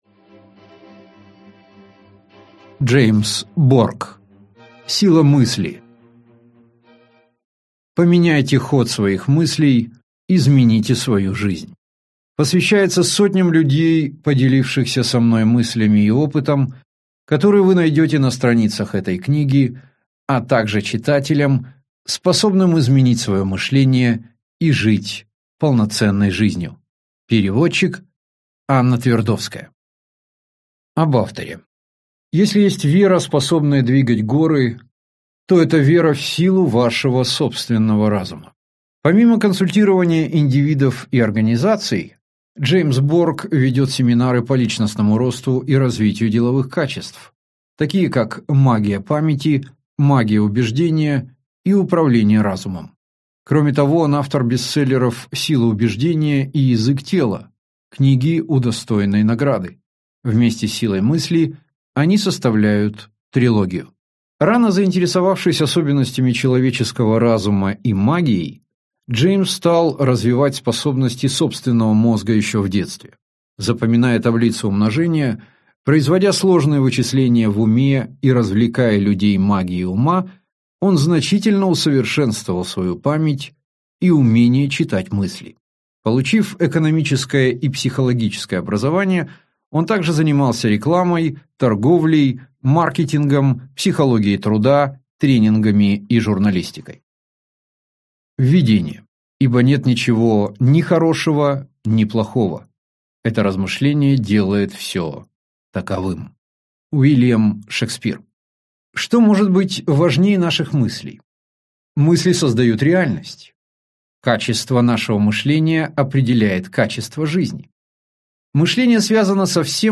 Аудиокнига Сила мысли. Поменяйте ход своих мыслей, измените свою жизнь | Библиотека аудиокниг